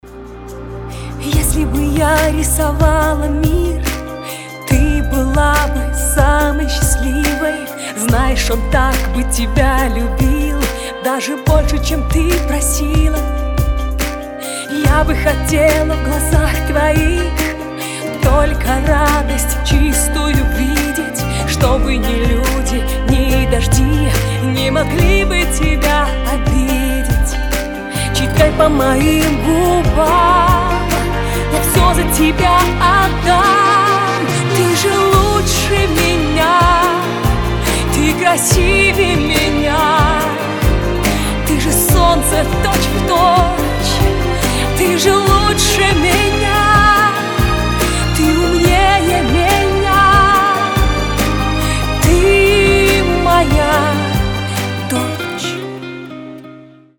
• Качество: 320, Stereo
душевные
спокойные
скрипка
баллада
нежные
трогательные